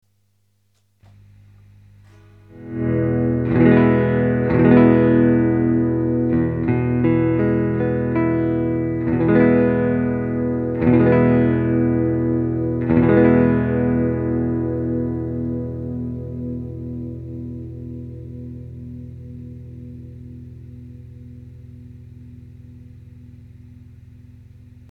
PUはネック＆センターにDP-217、リヤにDP-117だば。
篭った音だば。
■アンプ：Fender　Pro-Junior（15W)
■マイク：Seide　PC-VT3000/SHURE　SM57-LCE